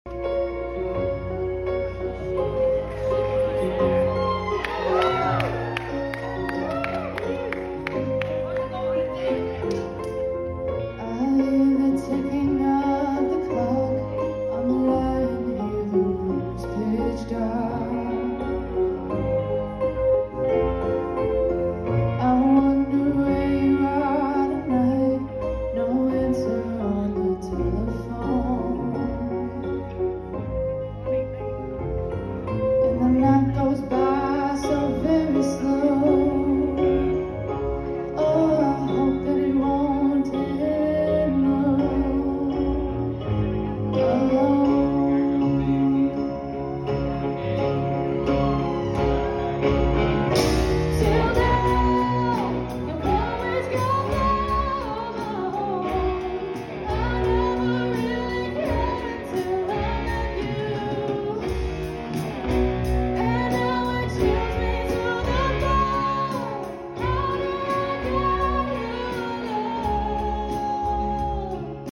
#80s